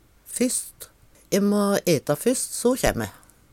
fysst - Numedalsmål (en-US)